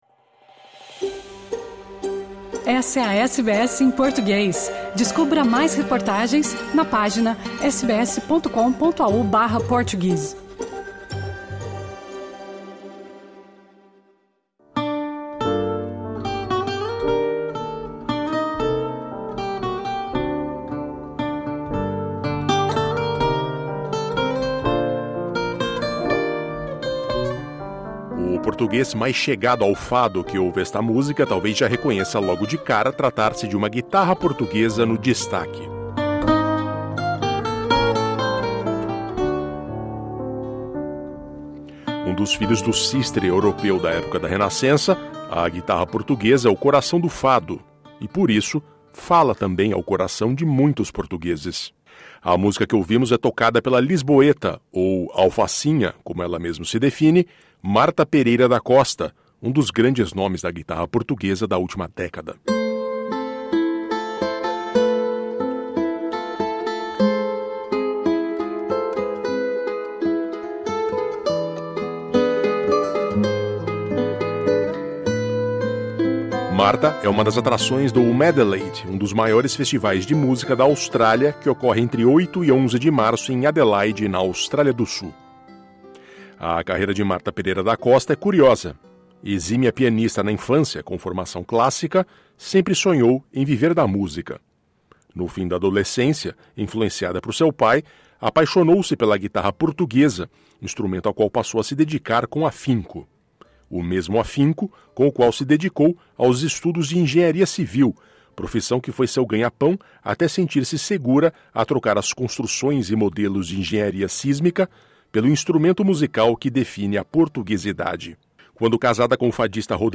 Nesta conversa com a SBS em Português, Marta Pereira da Costa contextualiza sua carreira, fala das semelhanças entre a guitarra portuguesa e o bandolim brasileiro, e a expectativa de visitar pela primeira vez a Austrália, país no qual tem familiares - eles viajarão desde Brisbane para prestigiar a parente famosa no festival na Austrália do Sul.